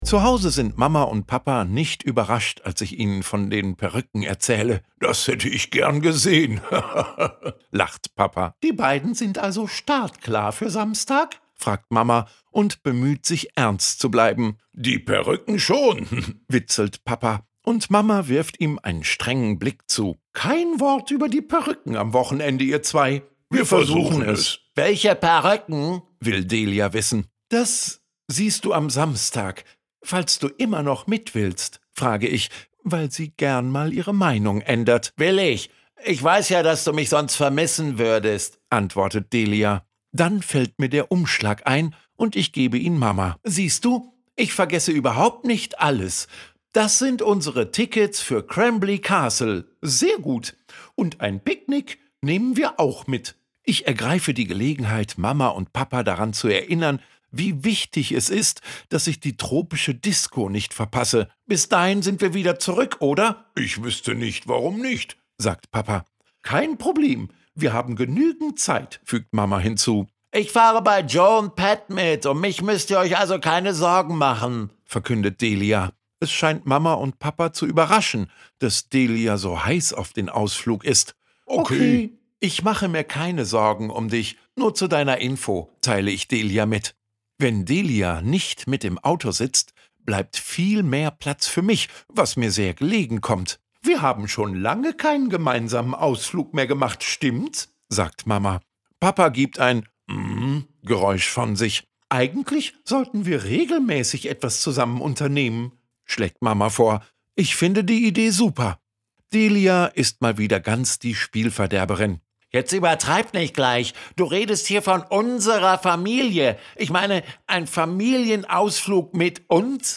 Hörbuch: Tom Gates 13.